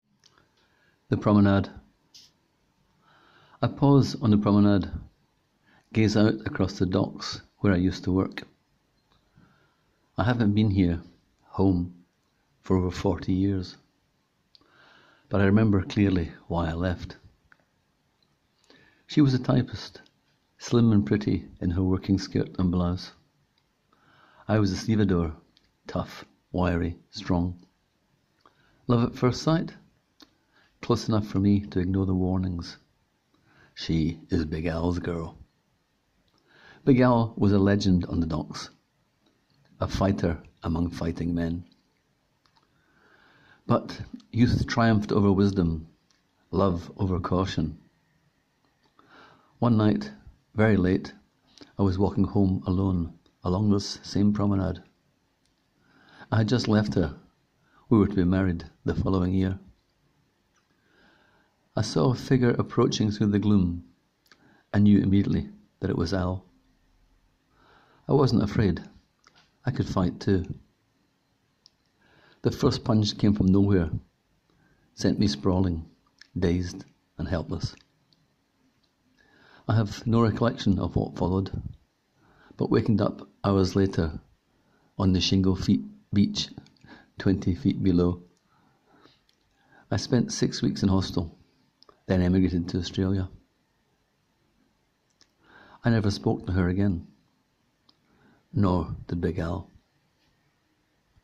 Click here to hear the author read his story: